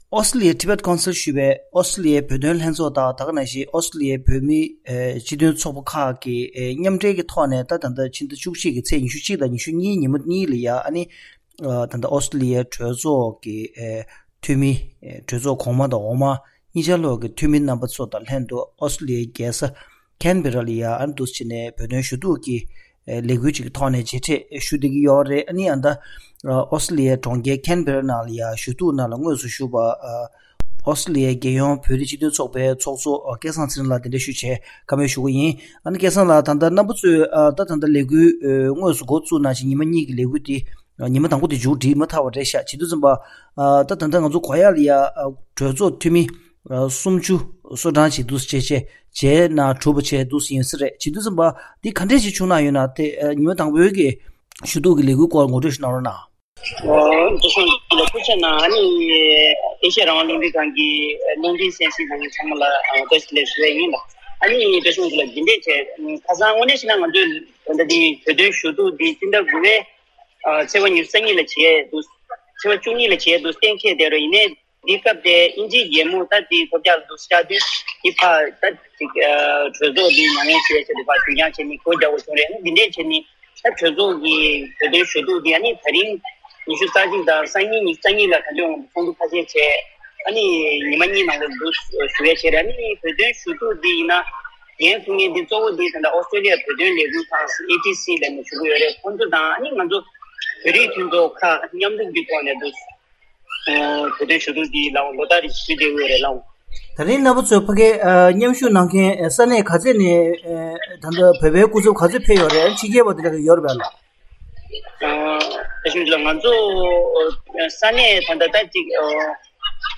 ཞུ་གཏུགས་དང་འབྲེལ་བའི་ཐད་བཀའ་འདྲི་ཞུས་པ་འདི་གསན་རོགས་གནང་།